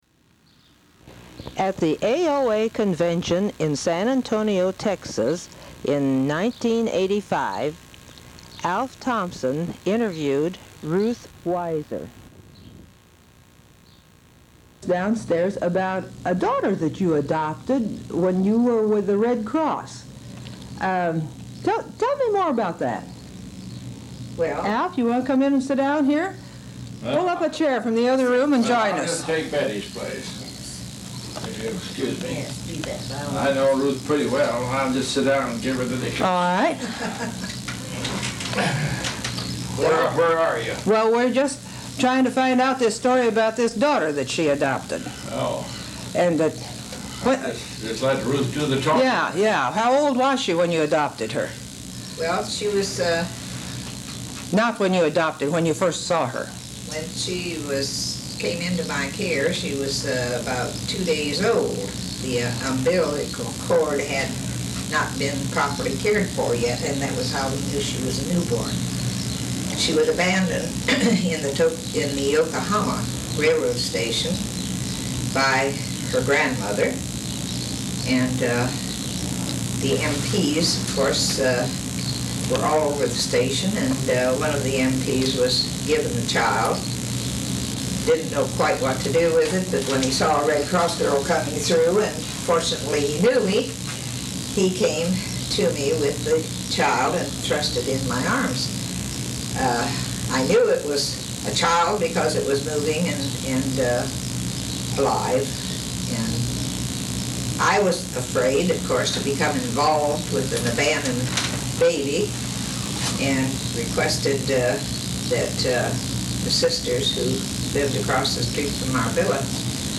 Recorded at the AOA Convention held in San Antonio, TX.
Women's Overseas Service League Oral History Project